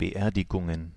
Ääntäminen
Ääntäminen Tuntematon aksentti: IPA: /bəˈʔeːɐ̯dɪɡʊŋən/ Haettu sana löytyi näillä lähdekielillä: saksa Käännöksiä ei löytynyt valitulle kohdekielelle. Beerdigungen on sanan Beerdigung monikko.